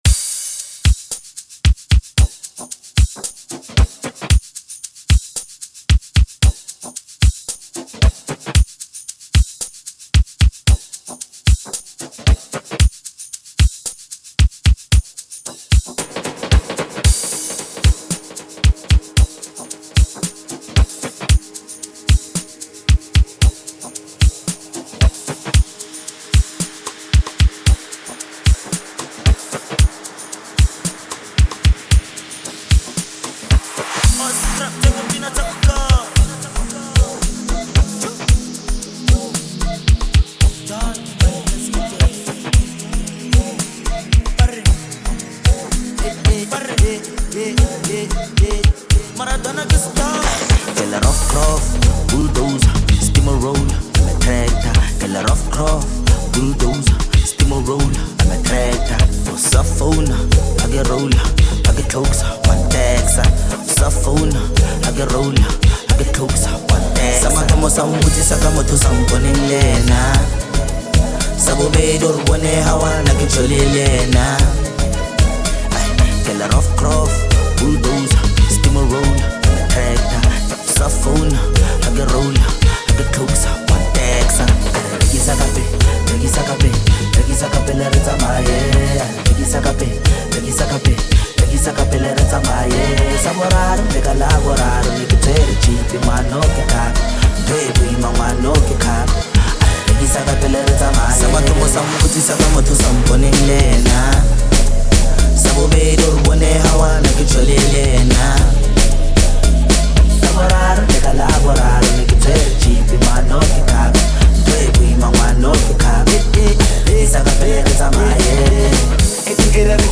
Amapiano
irresistible, pulsating rhythm